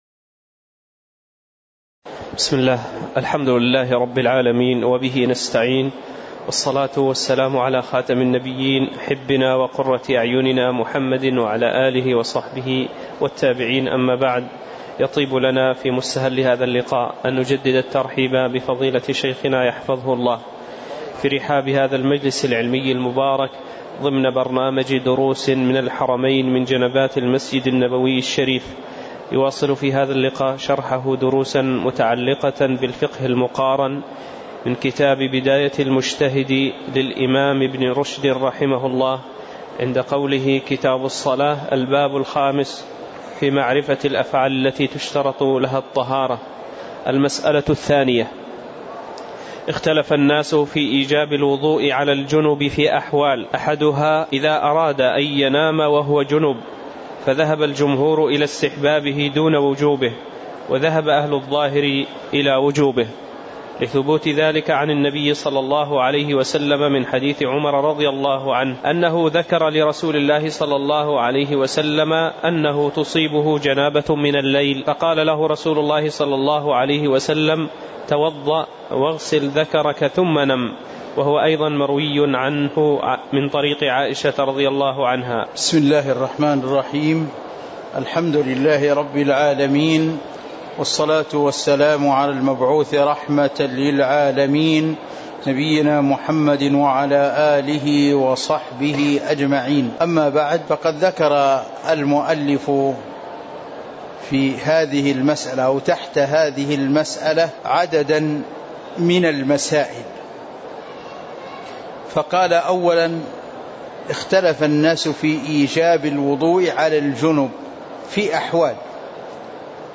تاريخ النشر ١٠ ربيع الأول ١٤٤٠ هـ المكان: المسجد النبوي الشيخ